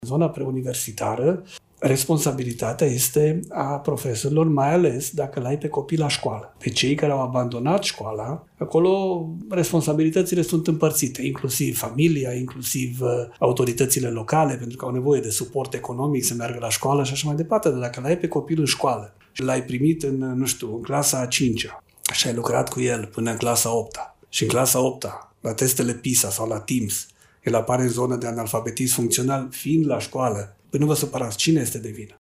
Reamintim că ministrul Educației, Daniel David, a declarat, în cadrul unui podcast organizat de  organizația Salvați Copiii,  că principala cauză a analfabetismului funcțional în rândul elevilor este lipsa de pregătire a cadrelor didactice. Oficialul a subliniat că, în cazul în care elevii ajung până în clasa a VIII-a fără să aibă competențe de bază, vina nu poate fi căutată în altă parte.